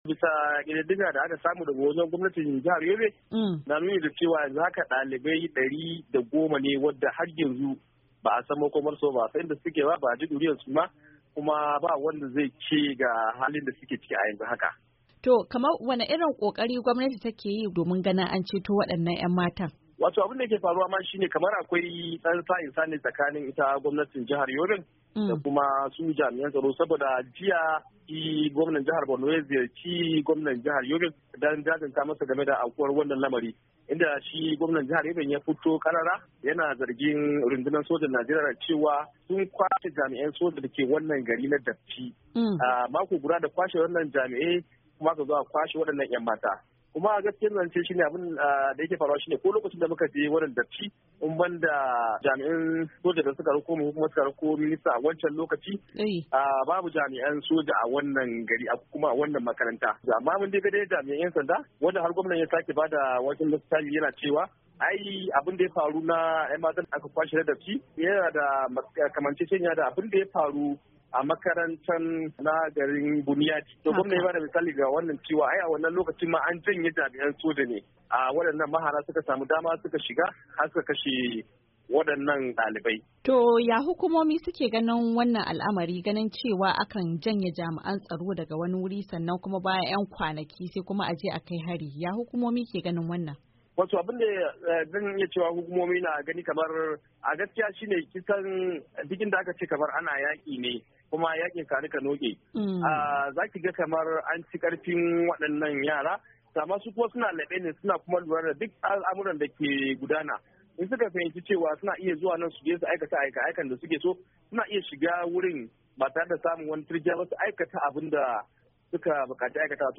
Saurarin tattaunawar